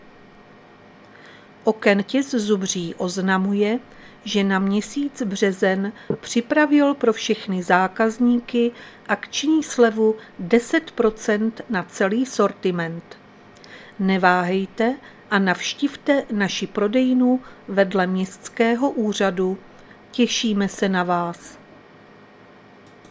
Záznam hlášení místního rozhlasu 13.3.2024
Zařazení: Rozhlas